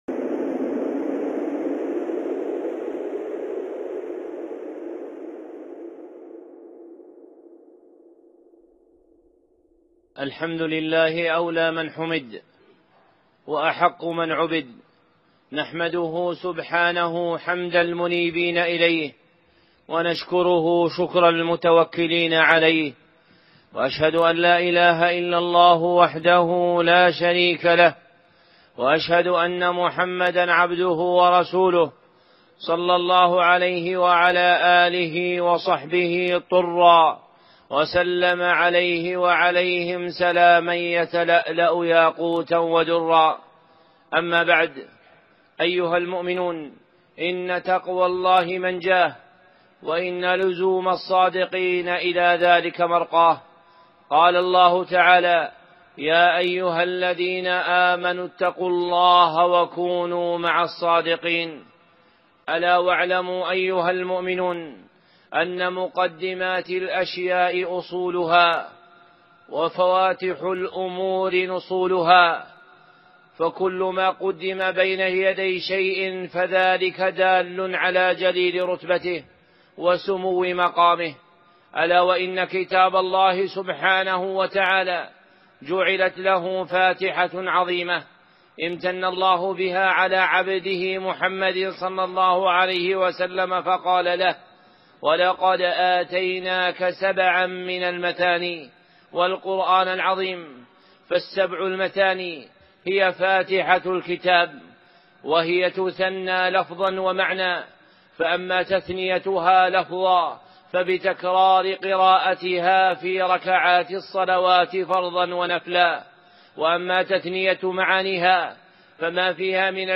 خطبة (أنوار الفاتحة